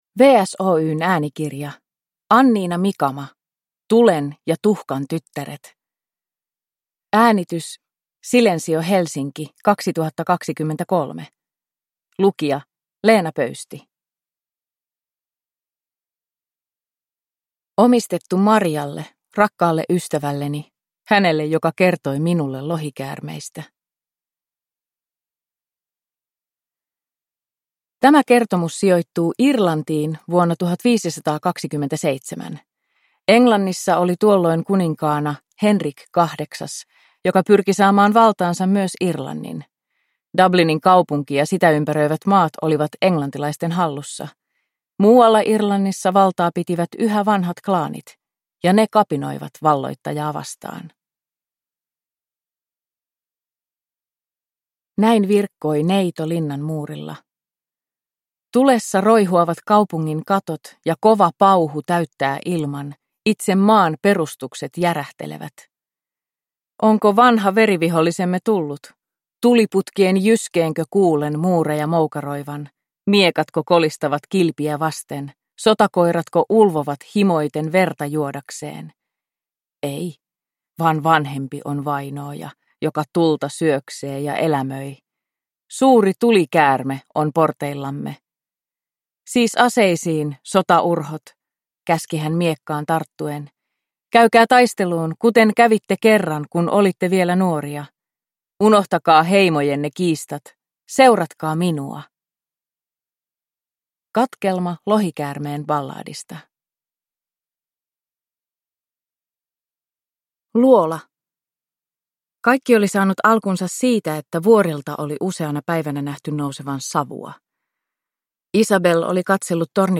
Tulen ja tuhkan tyttäret – Ljudbok